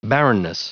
Prononciation du mot barrenness en anglais (fichier audio)
Prononciation du mot : barrenness